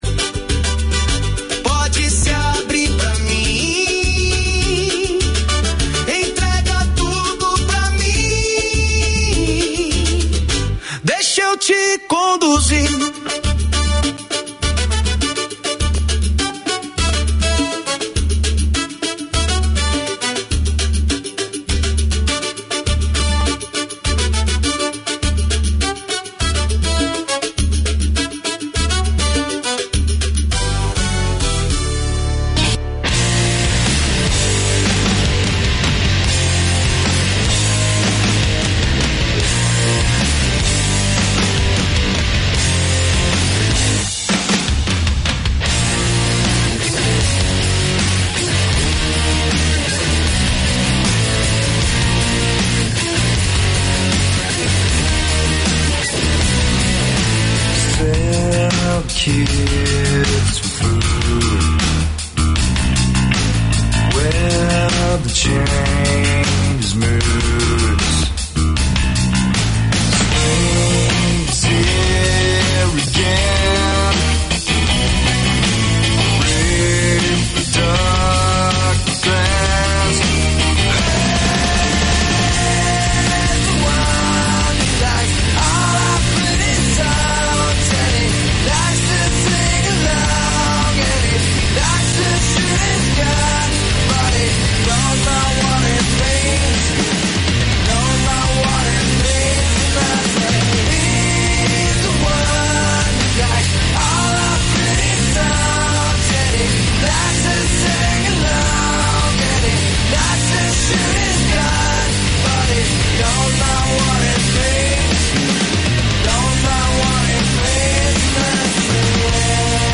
The talk is highlighted with the playing of bhjans.